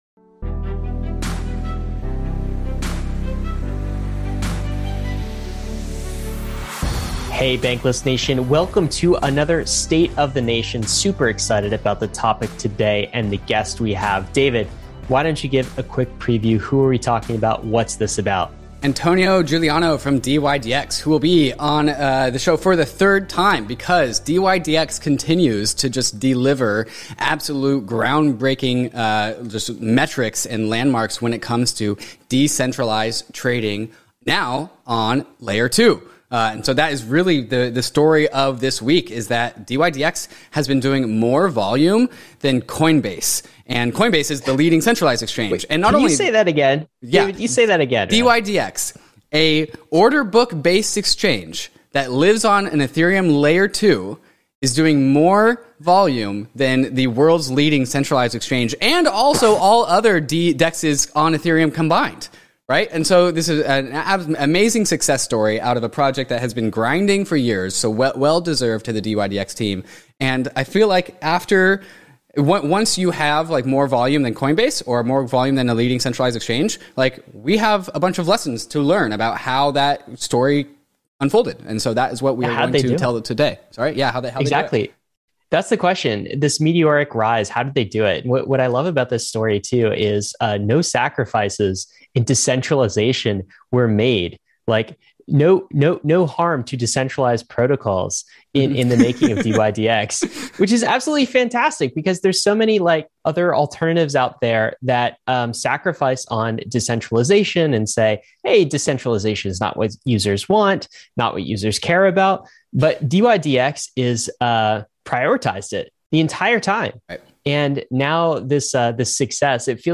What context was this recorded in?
State of the Nation is livestreamed on Tuesdays at 11am PT / 2pm ET.